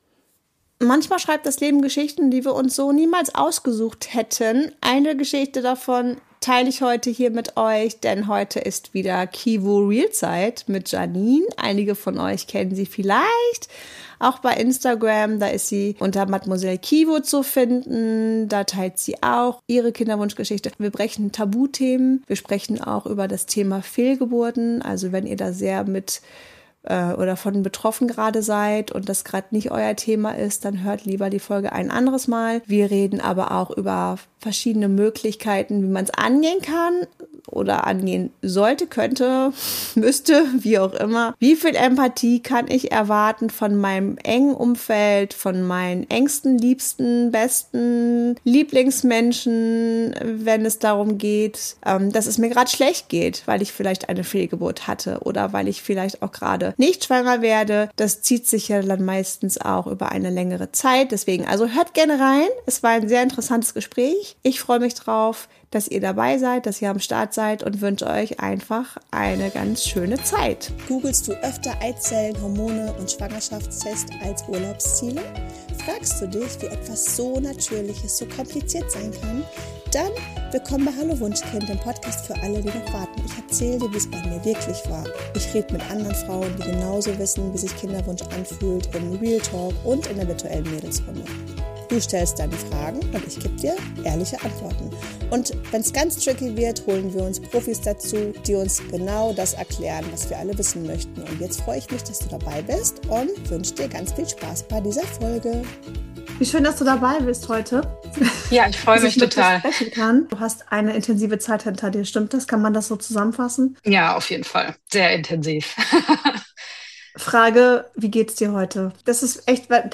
Diese Folge ist für dich, wenn du: – selbst auf deiner Kinderwunschreise bist – dich nach ehrlichen Gesprächen sehnst – oder einfach verstehen möchtest, was hinter diesen Themen wirklich steckt Ein Gespräch, das bewegt, verbindet und vielleicht auch etwas in dir auslöst.